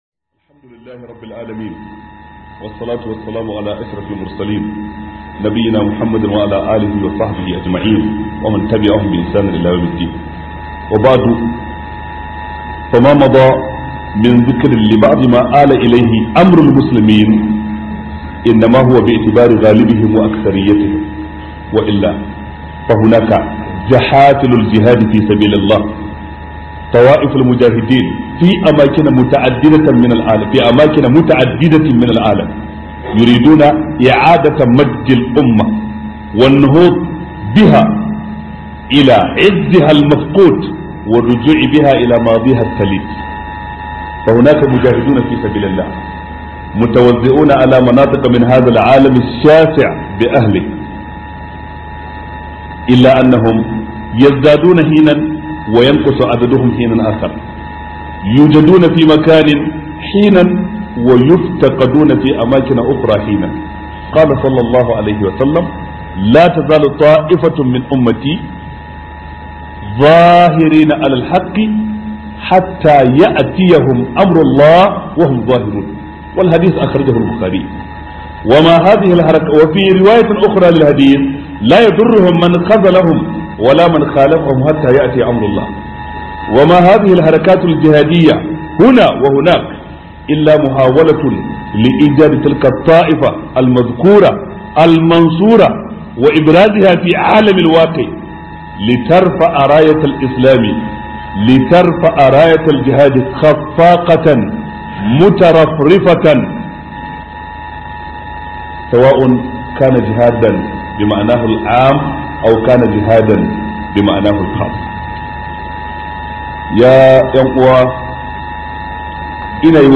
HUDUBA - Sheikh Jaafar Mahmood Adam
HUDUBA by Sheikh Jaafar Mahmood Adam